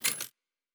pgs/Assets/Audio/Sci-Fi Sounds/Weapons/Weapon 06 Foley 3.wav at master
Weapon 06 Foley 3.wav